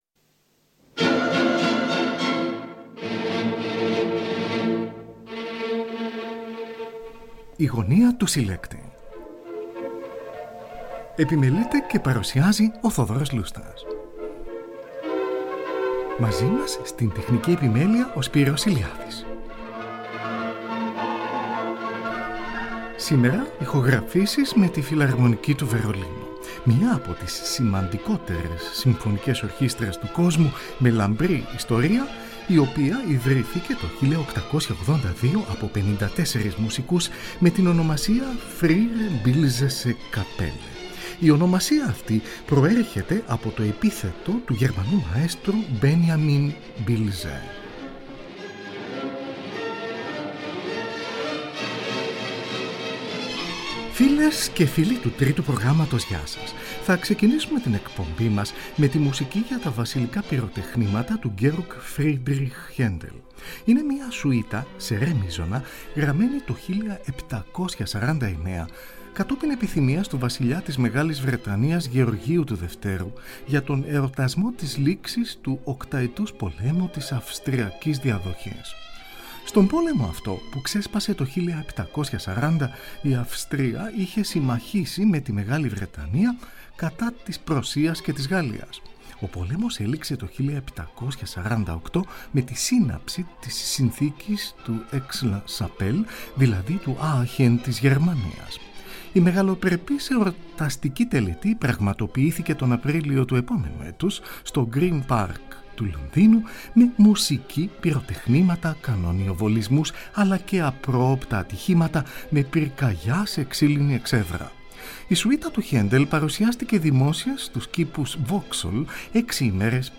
Ηχογραφήσεις της Φιλαρμονικής Ορχήστρας του Βερολίνου
από ζωντανή ηχογράφηση